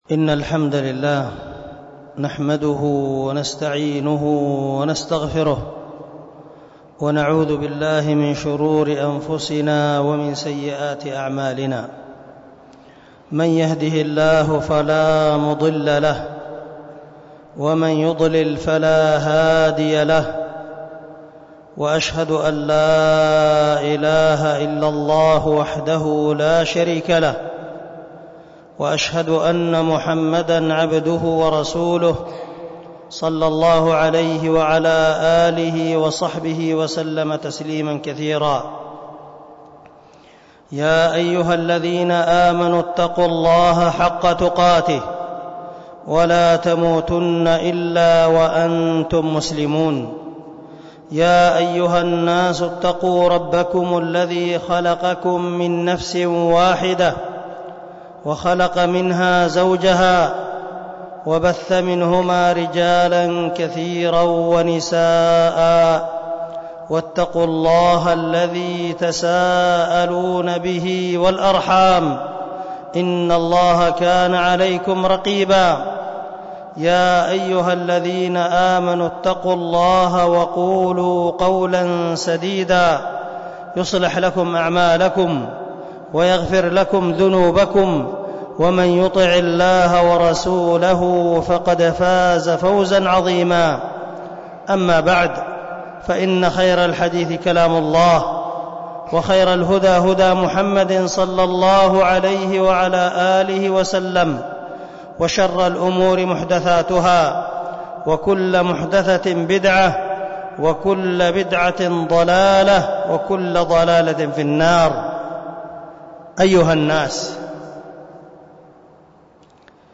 -خطبة الجمعة على أن من أركان الإيمان -العمل
خطبة-الجمعة-على-أن-من-أركان-الإيمان-العمل.mp3